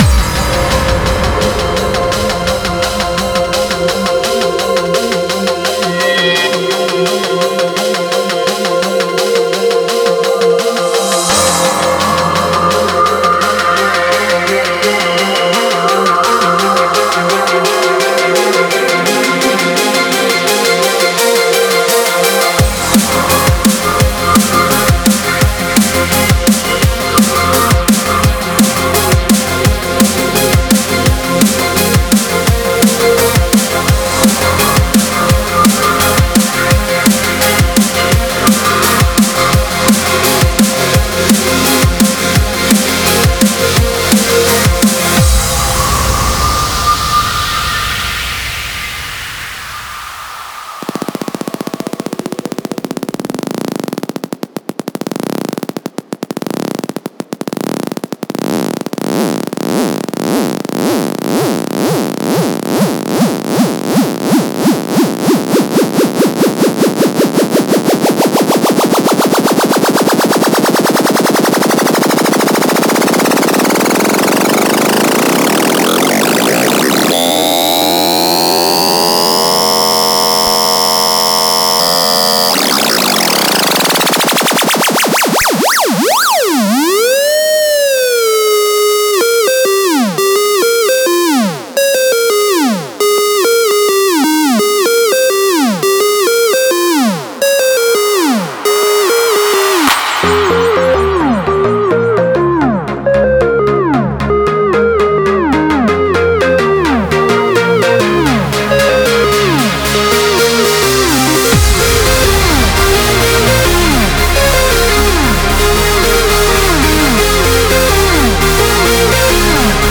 это энергичный трек в жанре хардстайл